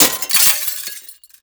GLASS_Window_Break_06_mono.wav